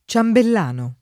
vai all'elenco alfabetico delle voci ingrandisci il carattere 100% rimpicciolisci il carattere stampa invia tramite posta elettronica codividi su Facebook ciambellano [ © ambell # no ] (antiq. ciamberlano [ © amberl # no ]) s. m.